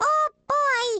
One of Baby Mario's voice clips from the Awards Ceremony in Mario Kart: Double Dash!!